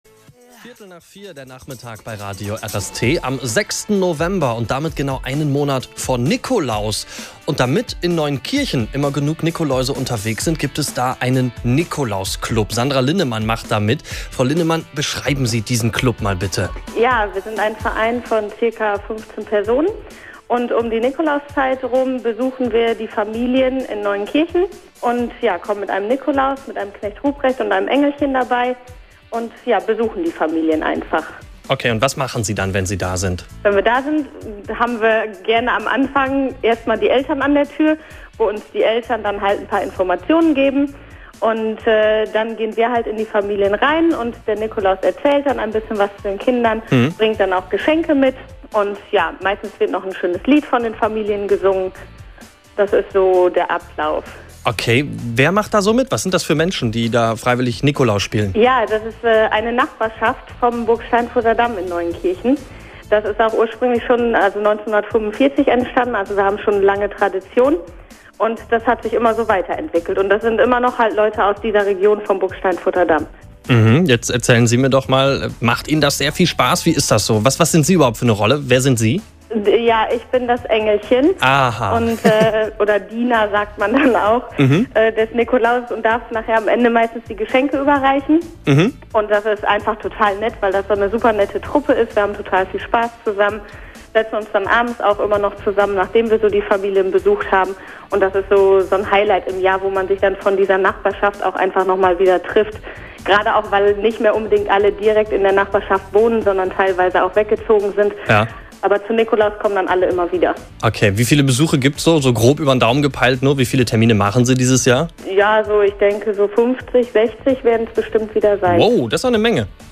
Mitschnitt vom 6.11.2012 Radio RST